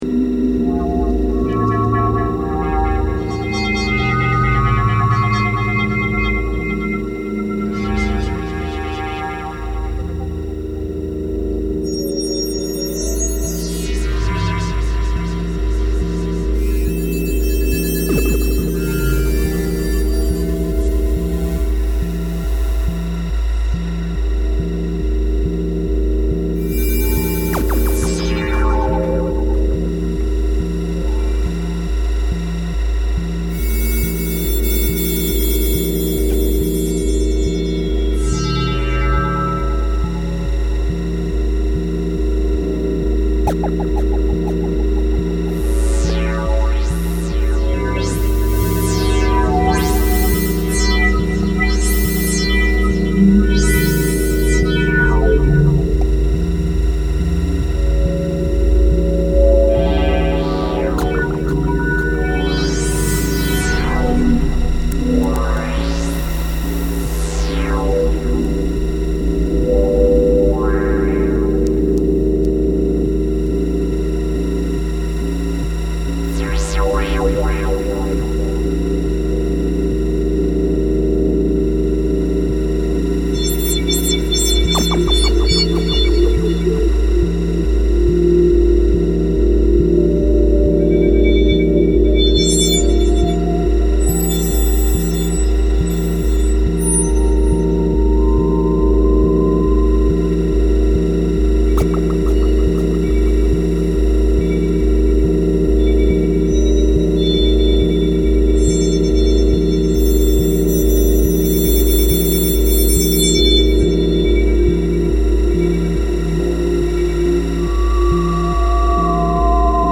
ambient/IDM
Electronix Techno